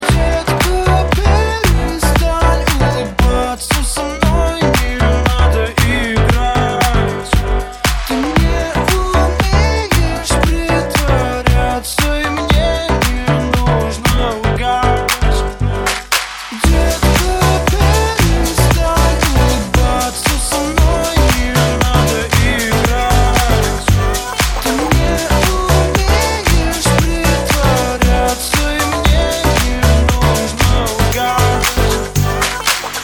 • Качество: 128, Stereo
поп
dance
club
клубнячок